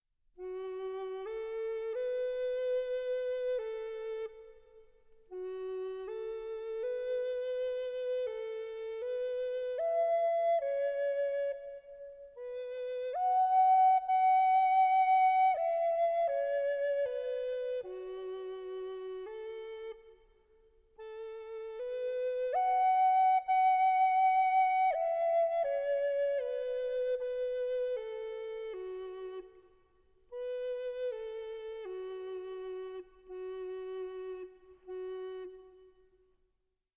crafted specifically for concert wind band
characterised not just by modes and the solo cedarwood flute